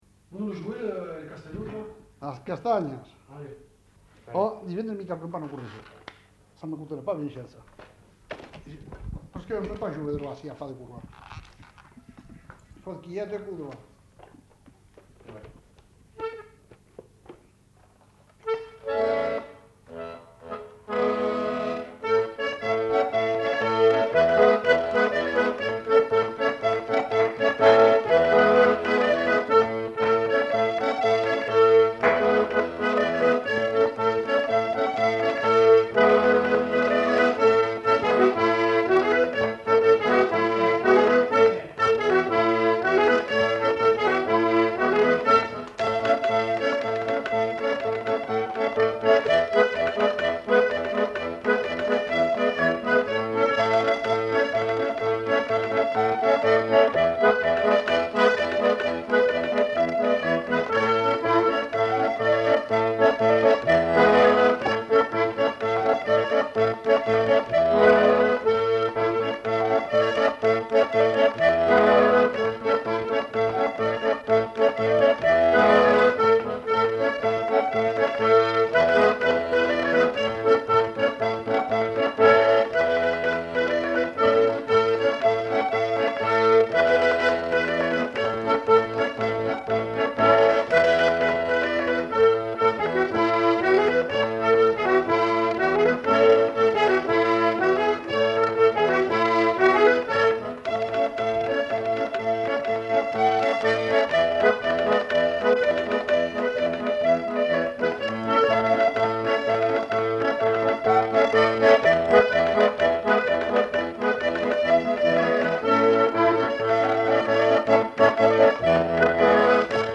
Lieu : Gardouch
Genre : morceau instrumental
Instrument de musique : accordéon diatonique
Danse : farandole